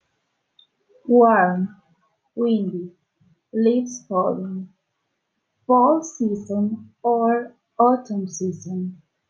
Flashcards con palabras e imágenes sobre las estaciones y palabras clave de elementos asociados del clima en ingles, puedes escuchar la pronunciación haciendo clic en el botón play.